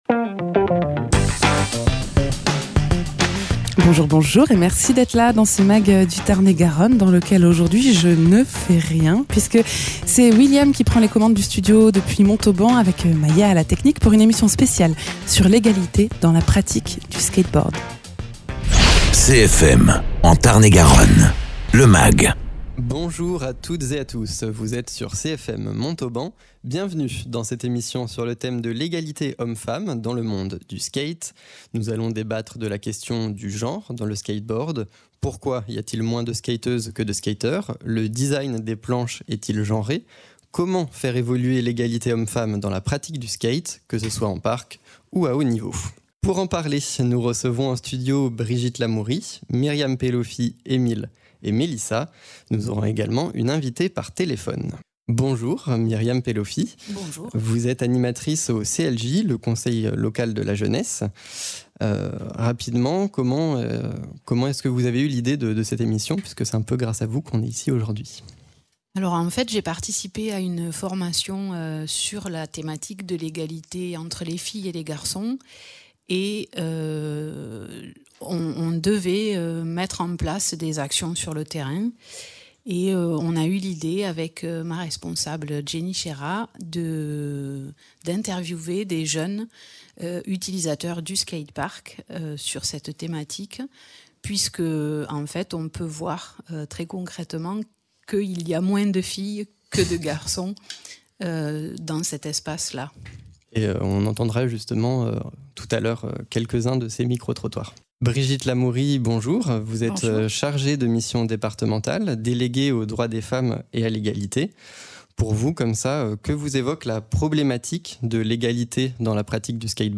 Emission spéciale sur l’égalité homme-femmes